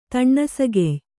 ♪ taṇṇasagey